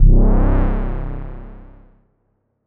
Synth Stab 09 (C).wav